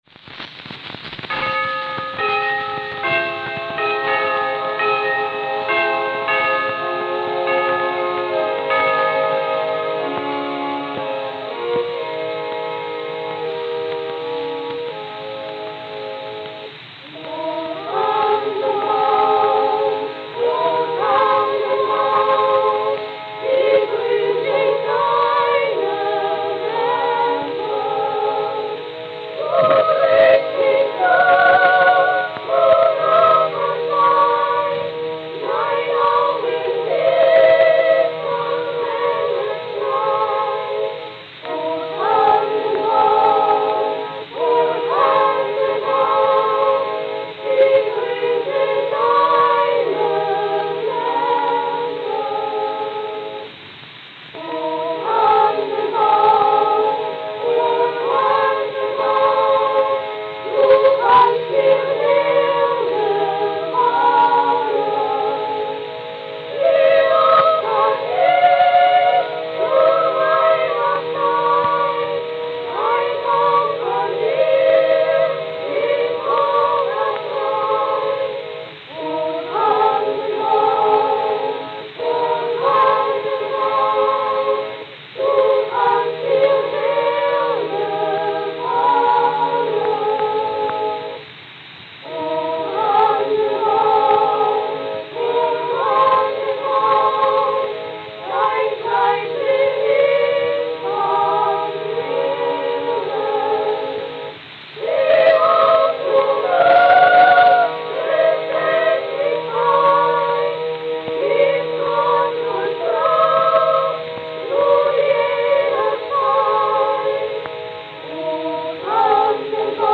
Victor 10-Inch Double-Sided Acoustical Records
Kinderchor Kinderchor
Berlin, Germany Berlin, Germany